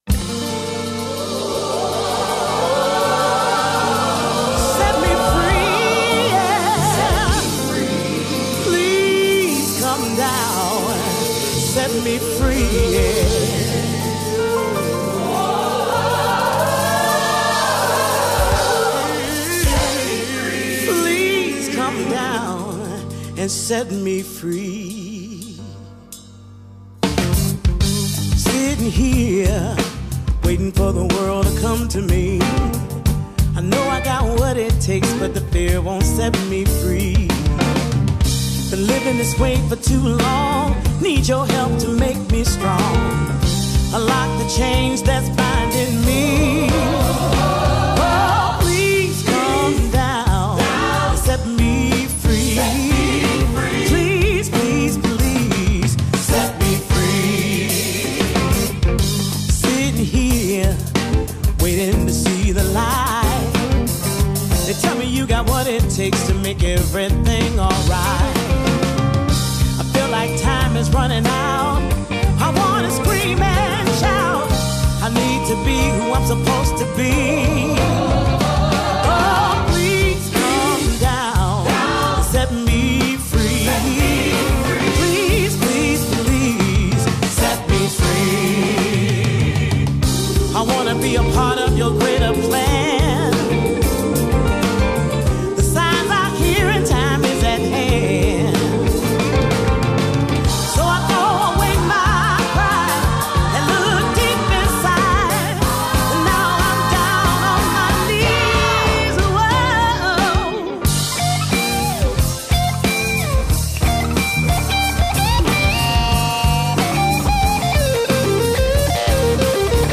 Y es que, durante la siguiente hora gozaremos con los ganadores de la Blues Foundation, en bittorrent, UPVRadio y esta misma web.